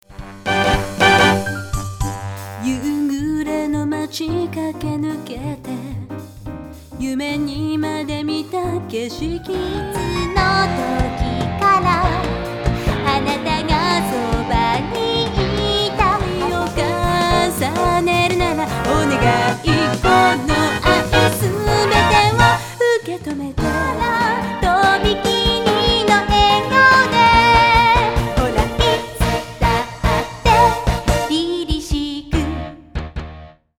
vocal song introduction (Trk.02,08)